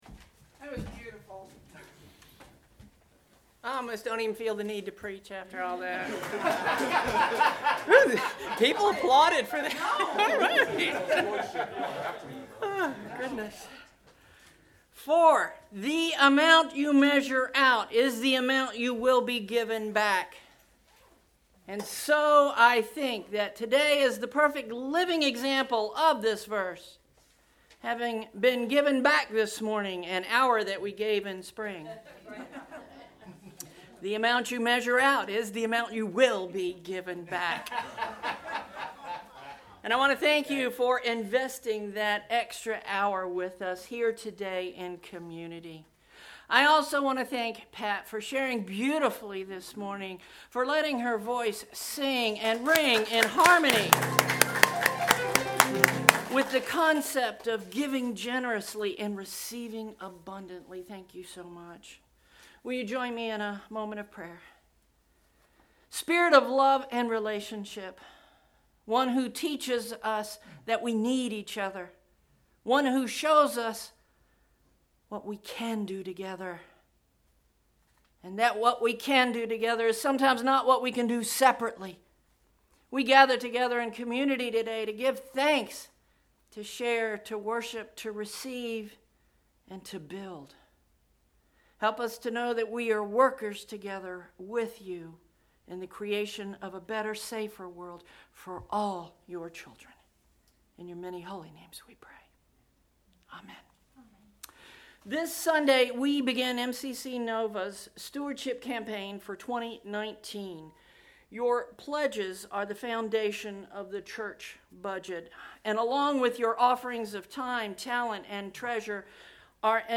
click to download 11/04 Sermon “Reflection: Mutuality” Preaching